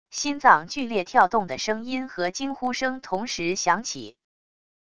心脏剧烈跳动的声音和惊呼声同时响起wav音频